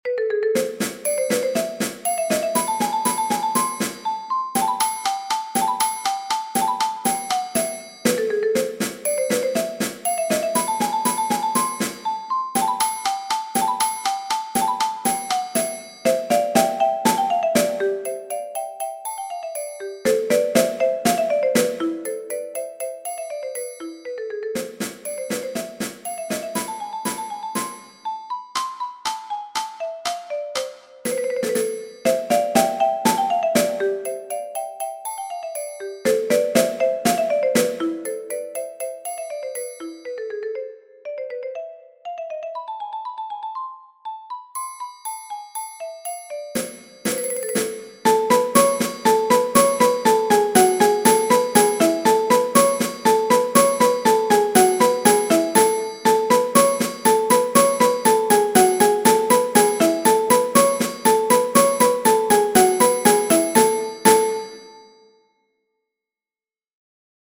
marimba solo
percussion trio